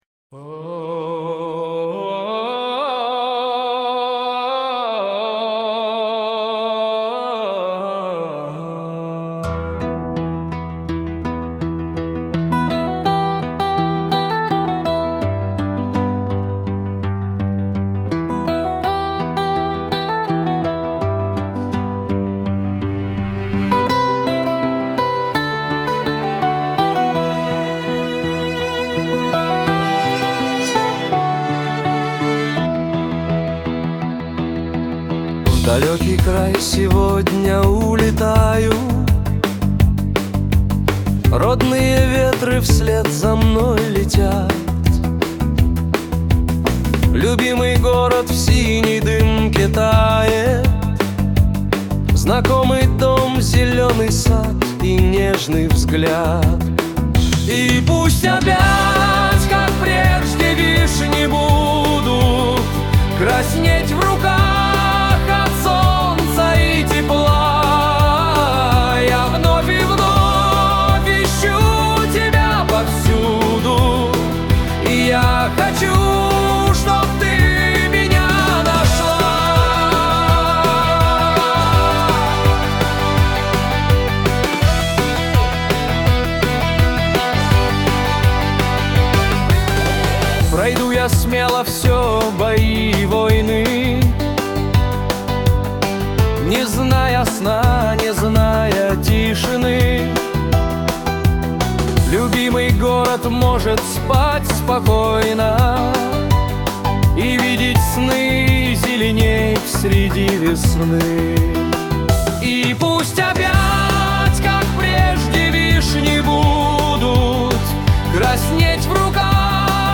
Вот, например, заготовка под титры к фильму (я постарался обойтись без рок-гитары, побольше клавишных, симфонизм и плотная музыка для кино, арабские мотивы):
Естественно, если это куда-то пойдёт — текст я напишу другой, с чистым копирайтом, а это так, пример аранжировки.